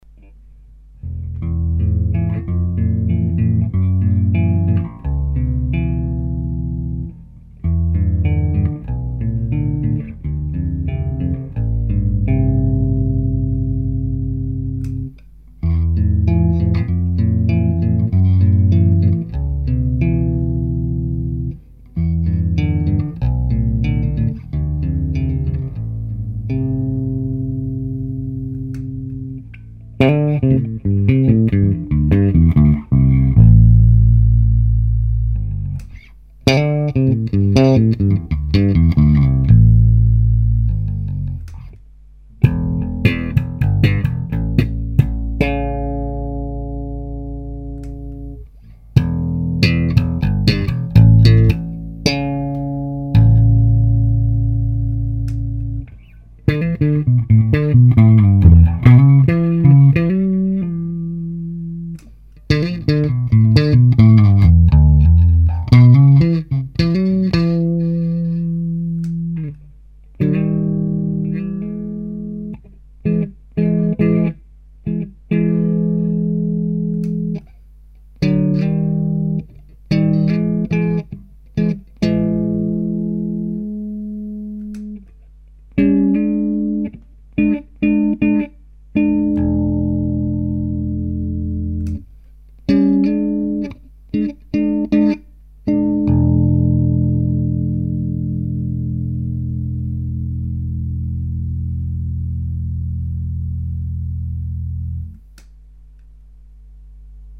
Als Abschluss des Tröööts noch eine Aufnahme, wo der Vergleich gut zu hören ist. War recht leise eingespielt , weswegen der Fußschalter noch zu hören ist. Aufnahme mit okm Mikros, eins mehr am Tweeter, eins mehr an der Pappe, Twetter bei ca. 2/5 auf, Bass Ibanez Talman Shorty an T900 Amp und schaltbarer Epifani-Box, First take : Anhänge BR_026.MP3 2,5 MB · Aufrufe: 299